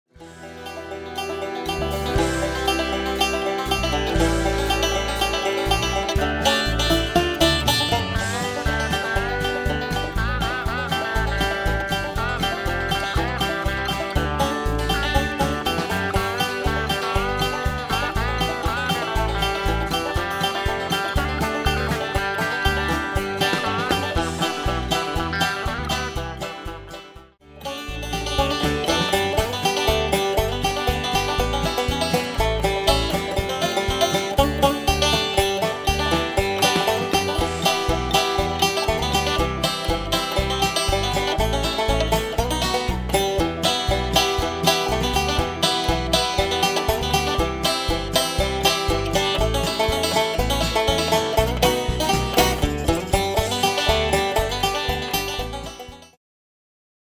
Sample   Lesson Sample